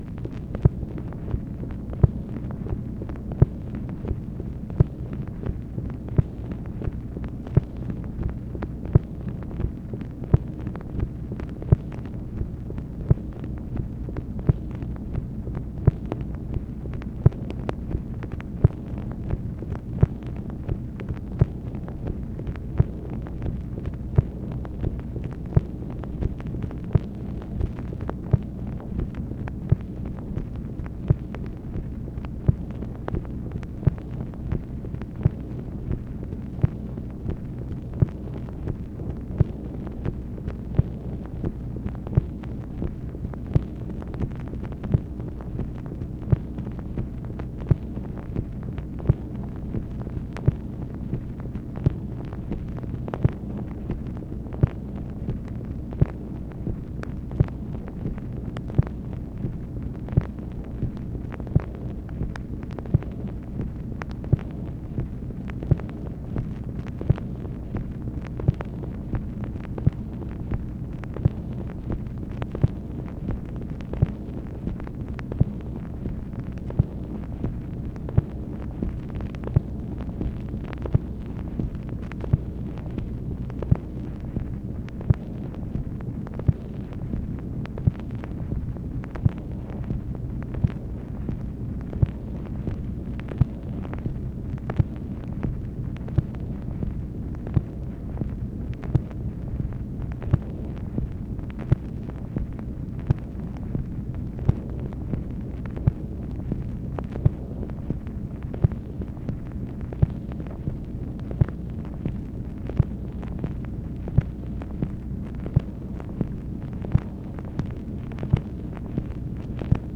MACHINE NOISE, November 23, 1964